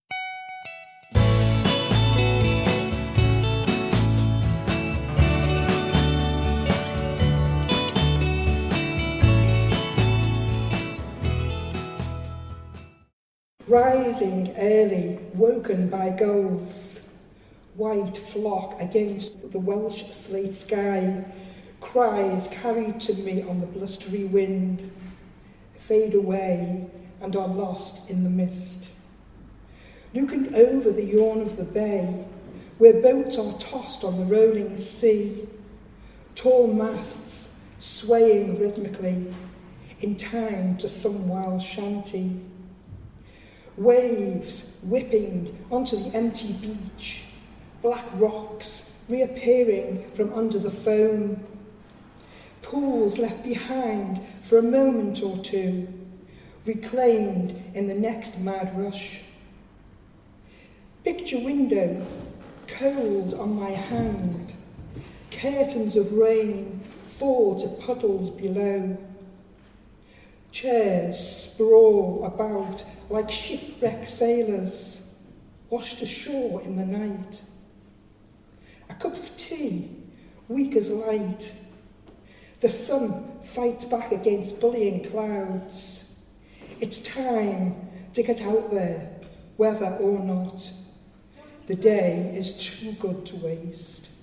a poem